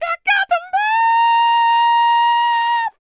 DD FALSET038.wav